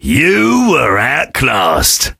sam_kill_vo_07.ogg